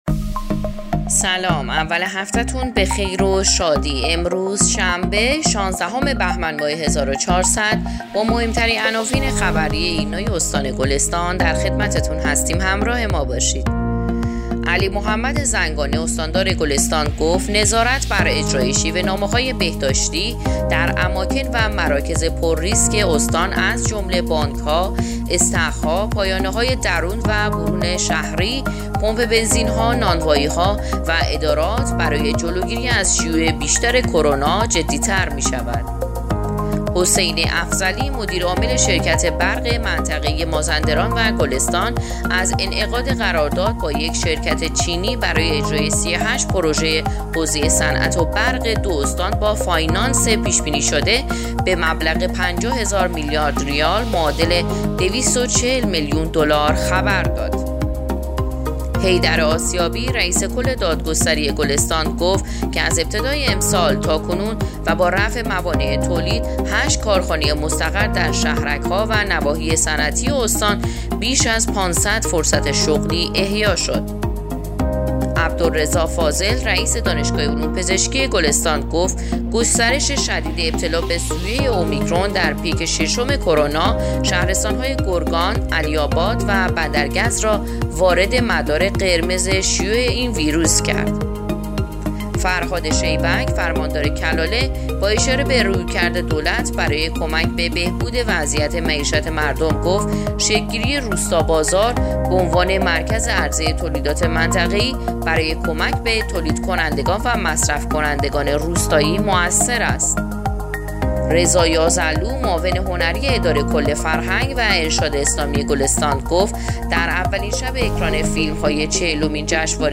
پادکست/ اخبار شبانگاهی شانزدهم بهمن ماه ایرنا گلستان